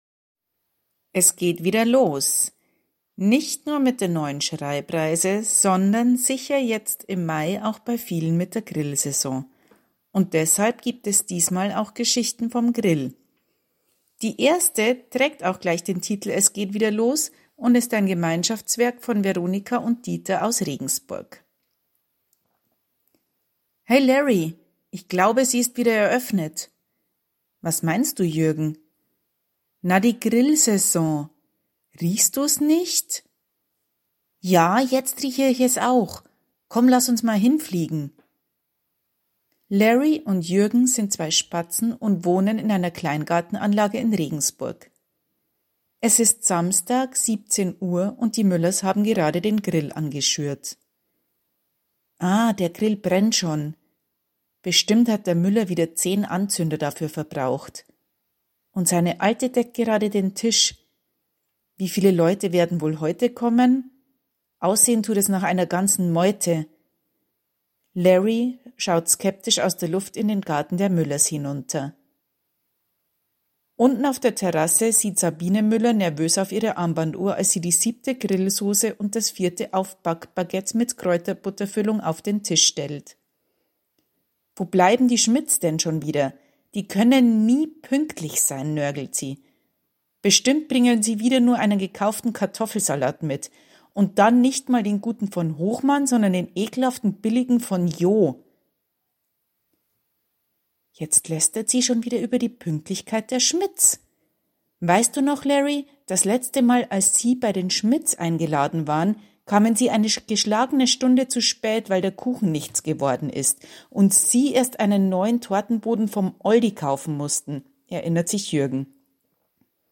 Schreibreise Podcast #6: vorgelesen von